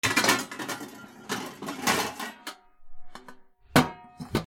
なべ 取り出す
『カラガラン』